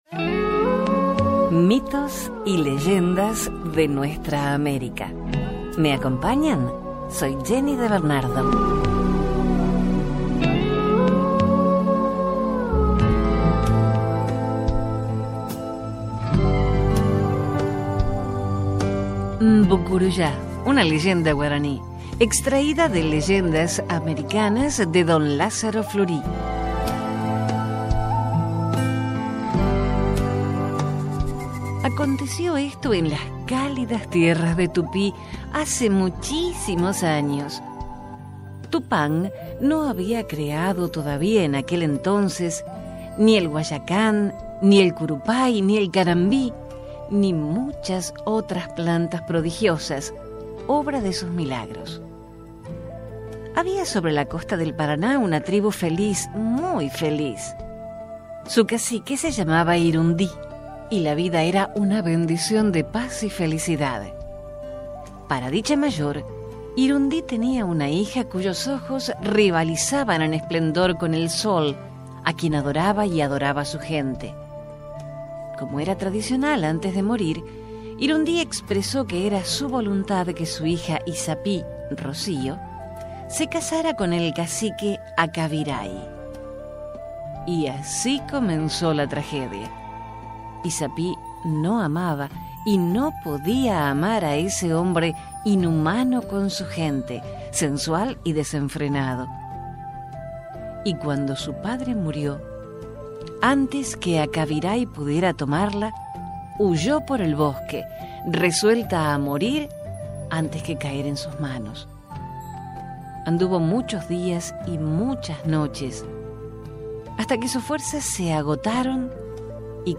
En mi caso, una gripe de las que hacen historia, por lo que mi voz está algo cascada.